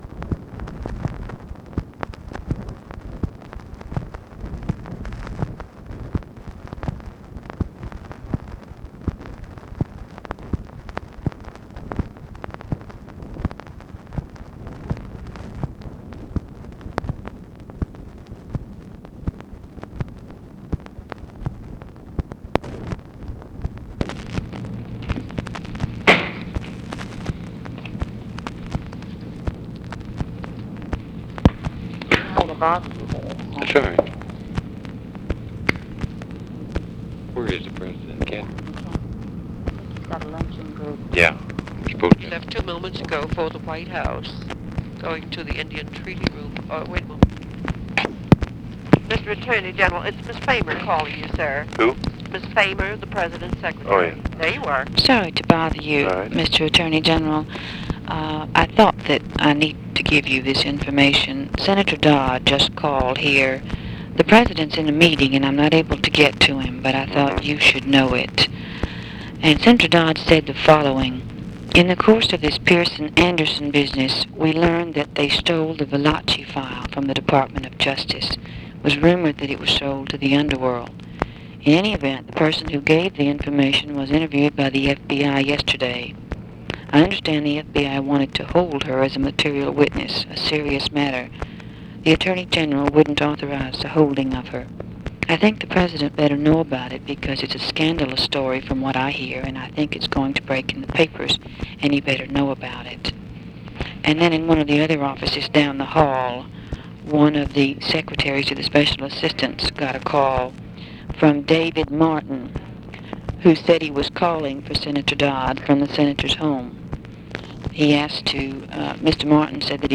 Conversation with NICHOLAS KATZENBACH, OFFICE CONVERSATION and TELEPHONE OPERATOR, April 2, 1966
Secret White House Tapes